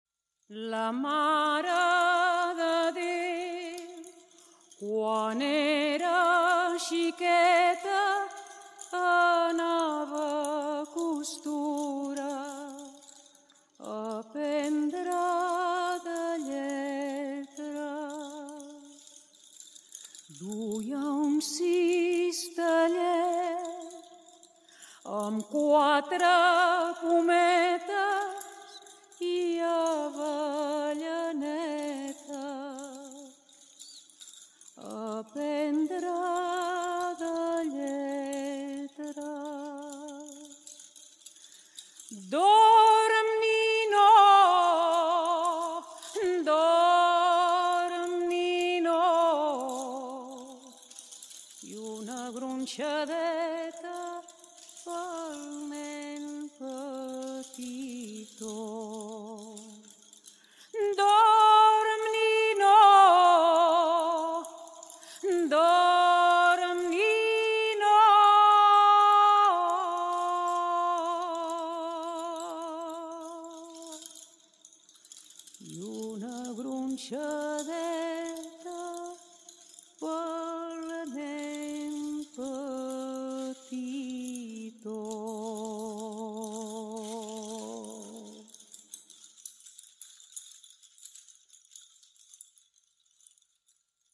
(premeu la imatge i podreu escoltar la cançó interpretada per Marina Rosell)